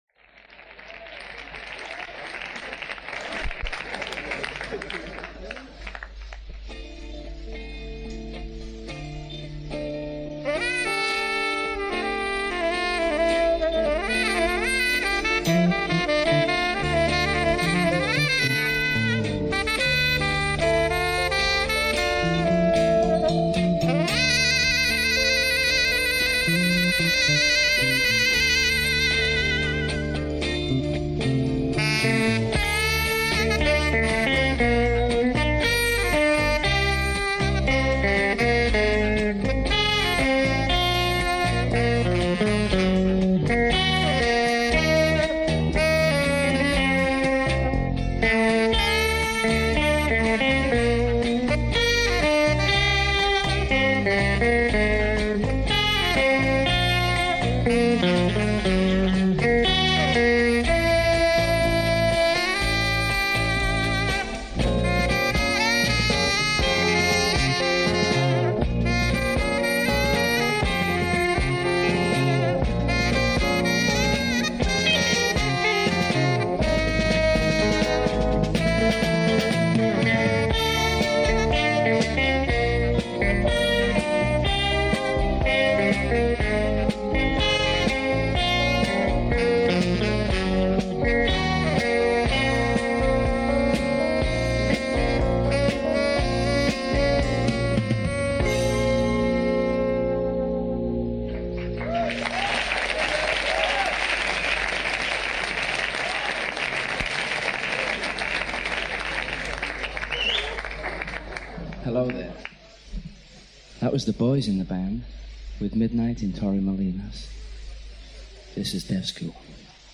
Art Rock